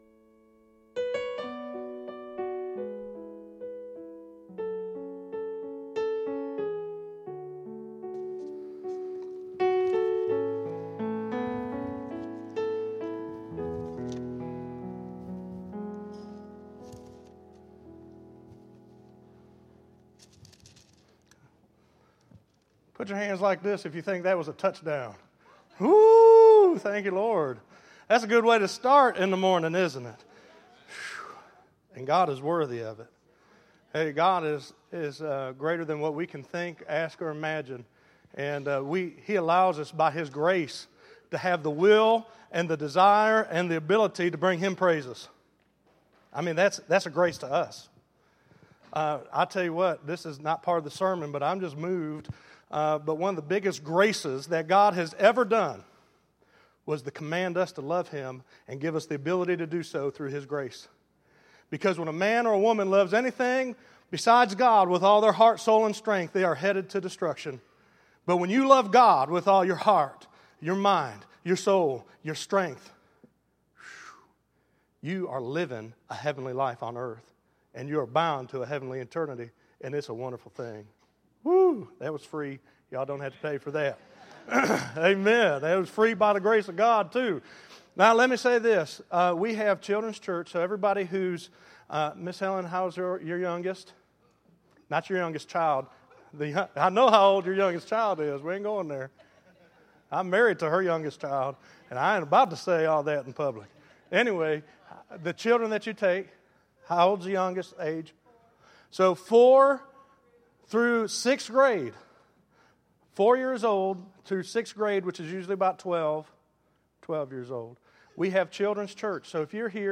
Listen to Rooting Out Bitterness - 01_12_14_Seermon.mp3